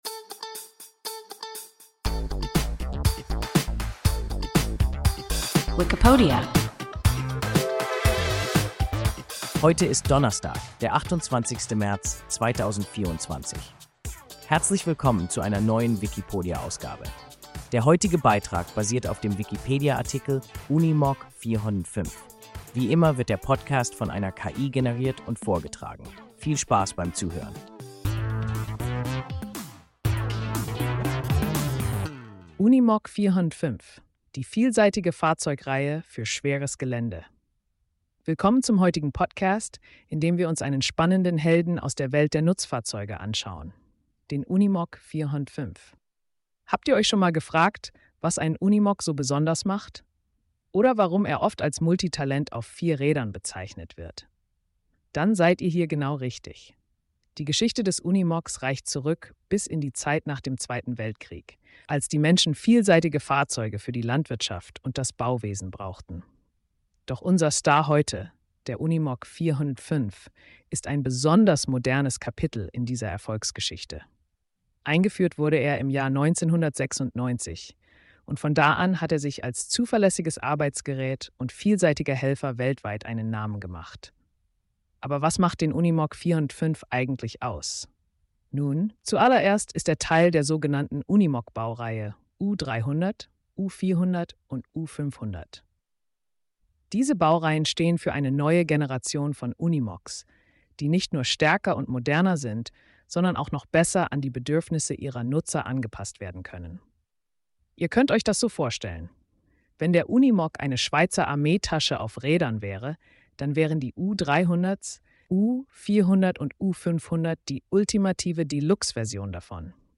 Unimog 405 – WIKIPODIA – ein KI Podcast